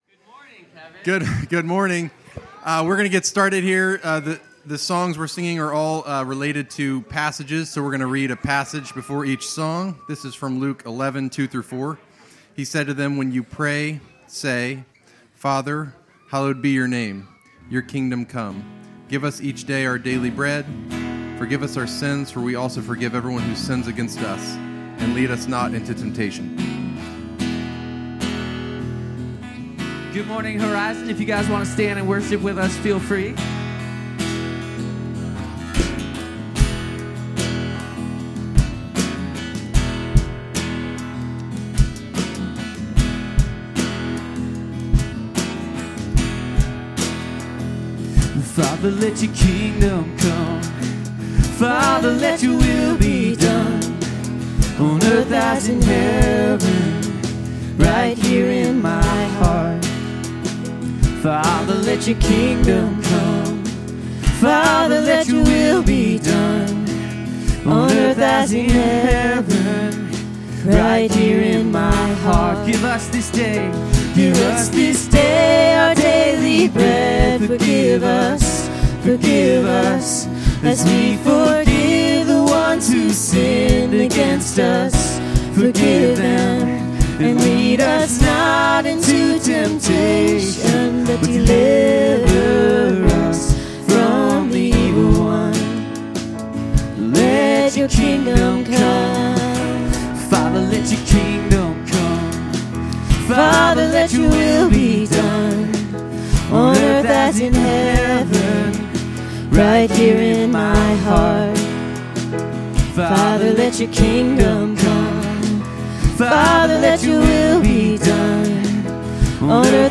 Horizon Reisterstown Worship 2026-02-01 Feb 01 2026 | 00:17:44 1x 00:00 / 00:17:44 Subscribe Share Apple Podcasts Spotify Amazon Music Overcast RSS Feed Share Link Embed